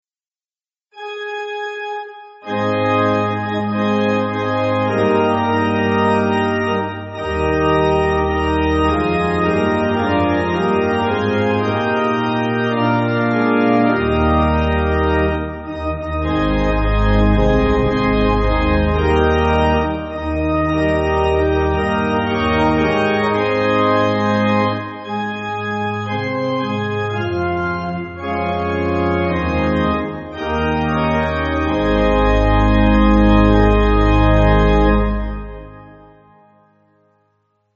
Organ
(CM)   1/Ab